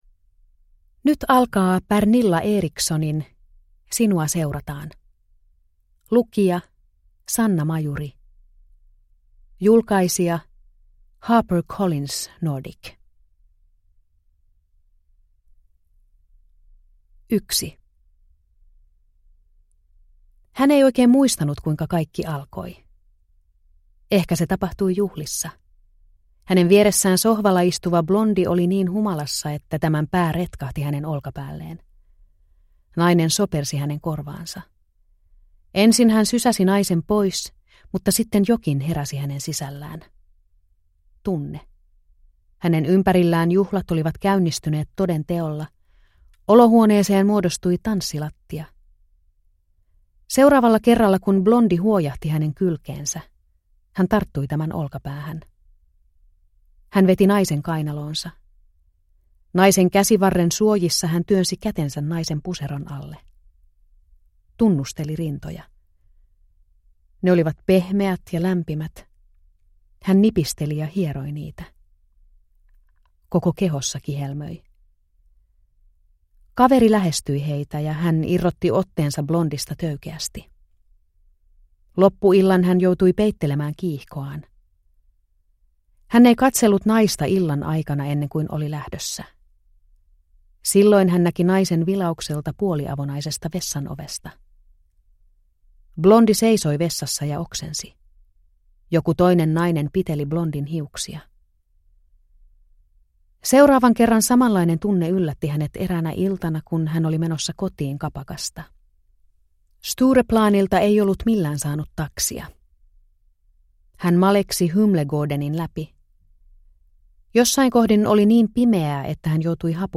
Sinua seurataan – Ljudbok – Laddas ner